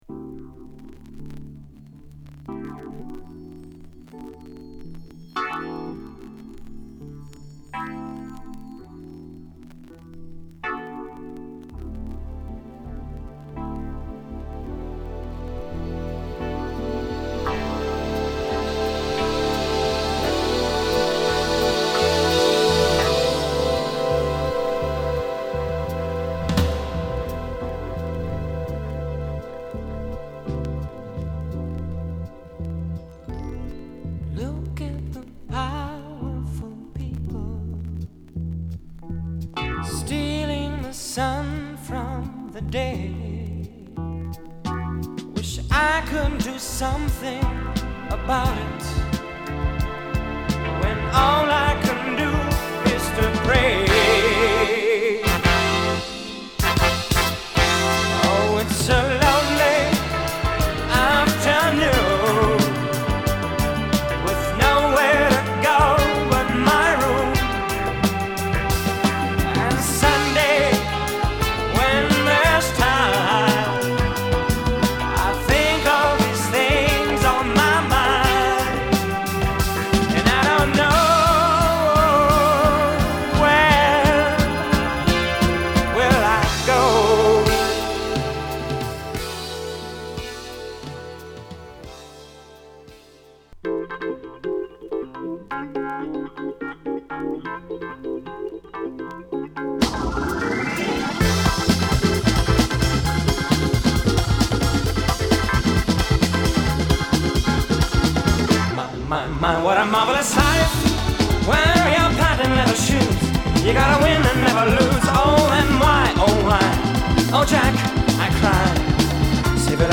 カナダ出身のブルーアイド・ソウル・シンガー